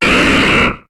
Cri d'Ectoplasma dans Pokémon HOME.